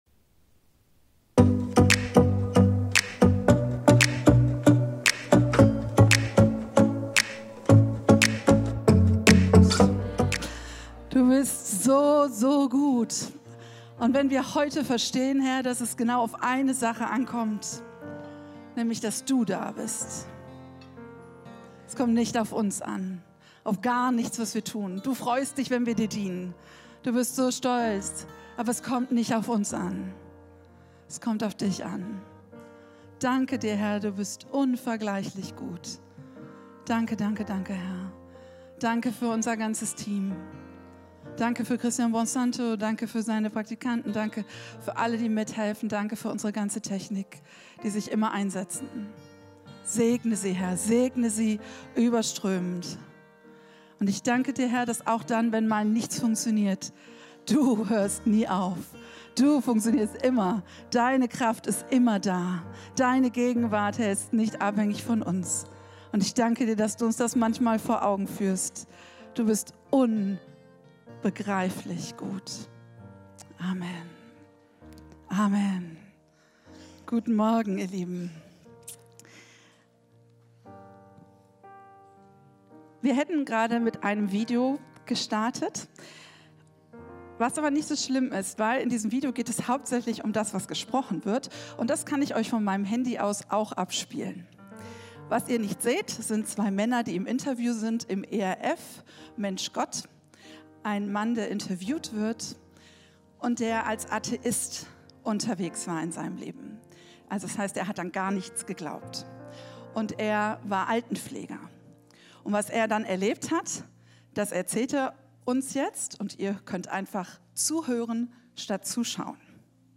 Video und MP3 Predigten
Kategorie: Sonntaggottesdienst